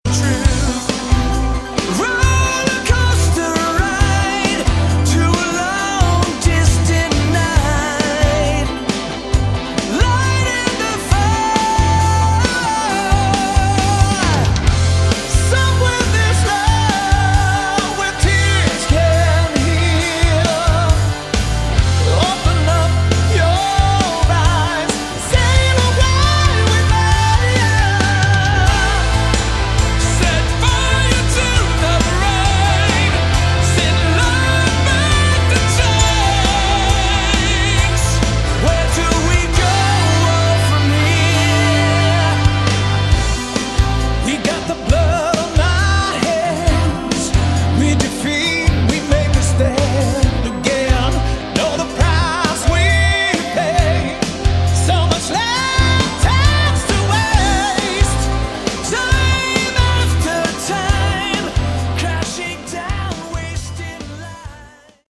Category: AOR
vocals
bass, additional keyboards, guitars,, backing vocals
guitars
drums